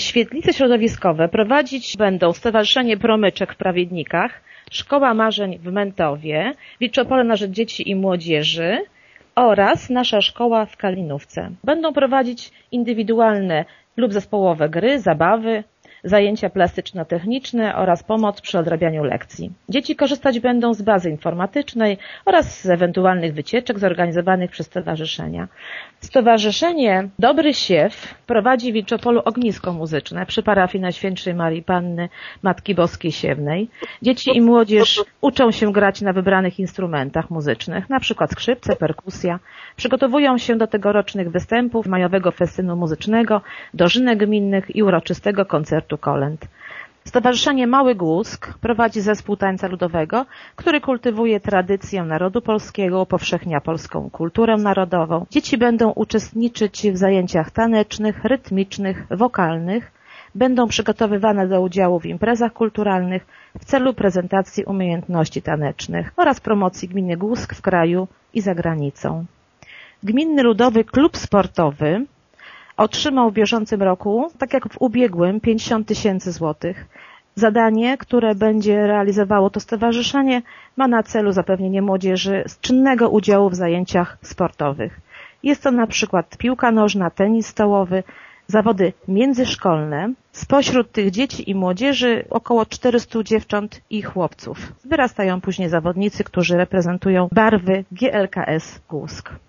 Środki na taką działalność otrzymają organizacje pozarządowe z Prawiednik, Mętowa, Wilczopola, Kalinówki i Głuska – informuje zastąpca wójta gminy Głusk Urszula Paździor: